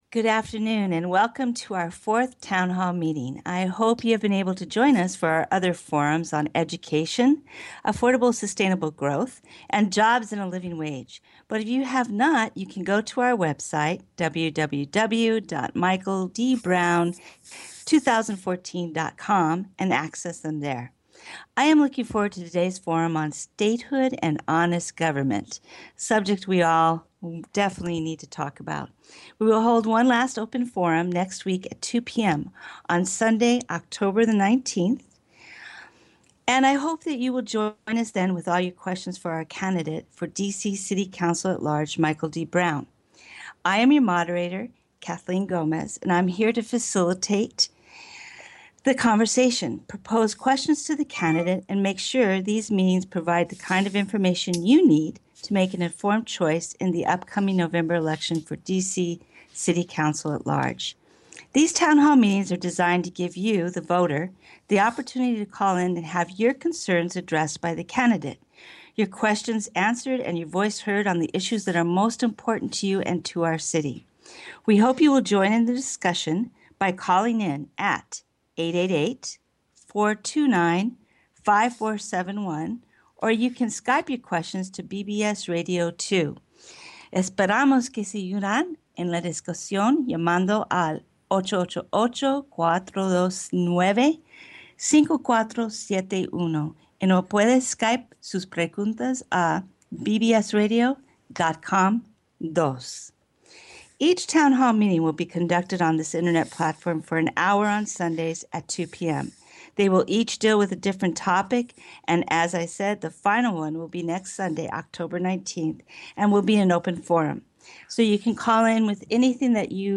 Town Hall Meetings with Senator Michael D. Brown